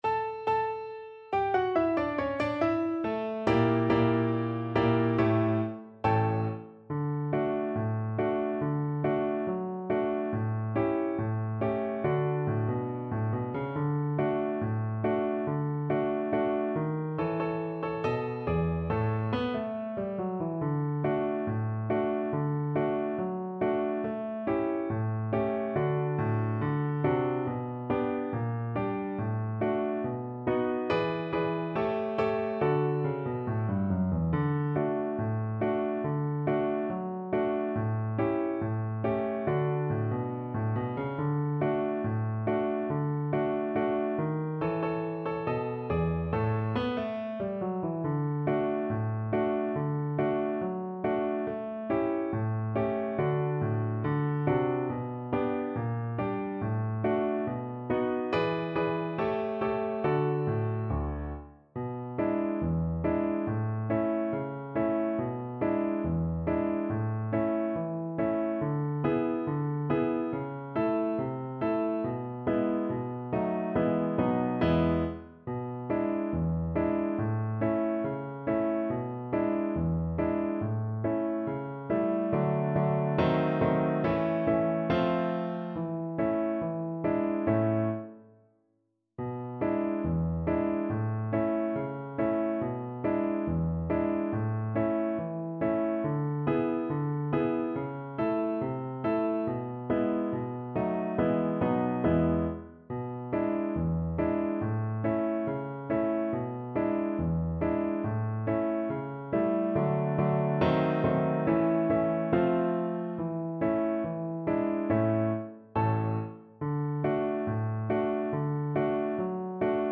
Not fast Not fast. = 70